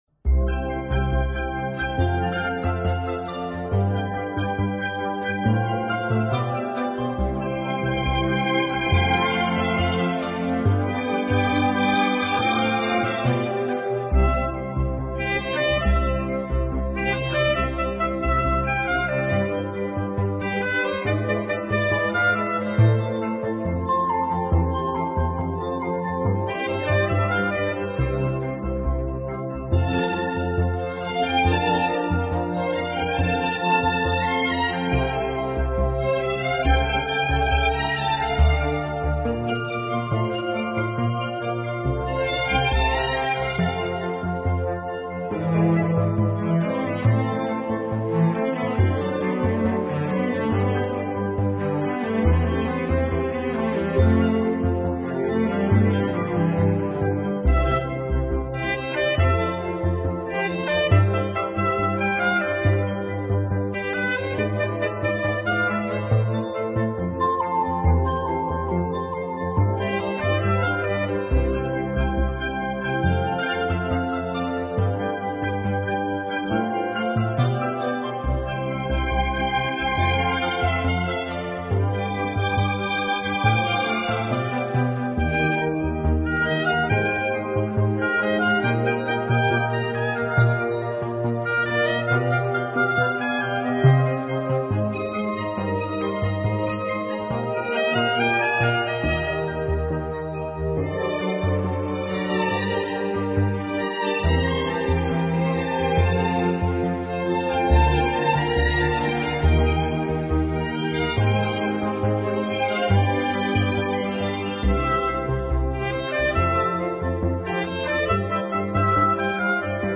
轻音乐专辑
音乐风格： 其他|古典|(Neo Classical)